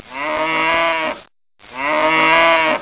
Cow Moo Download
Cowmoo.mp3